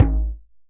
DJEM.HIT09.wav